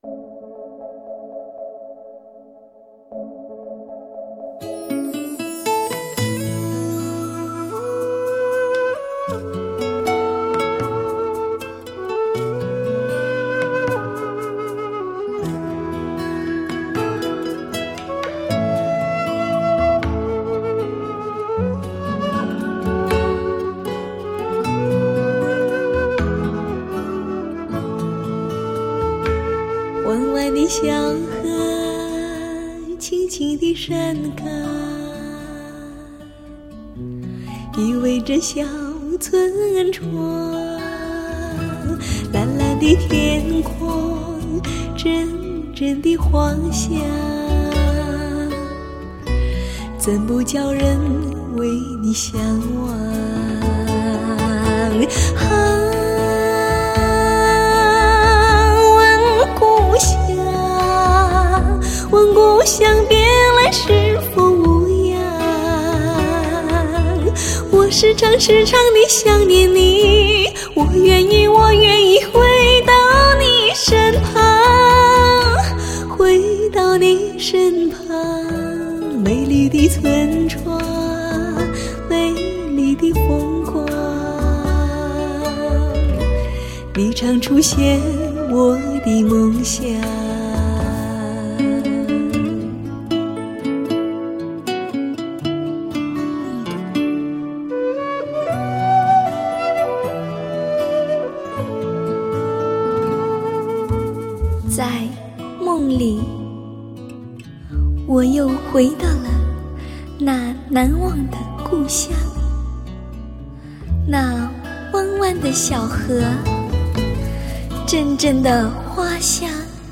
国际化的制作，历史性的突破，马头琴联袂新疆各民族乐器，曙光挥去黑暗，
琴声带走忧伤。
音色似山泉，气质若明月，轻描淡写之中尽现幽远意境。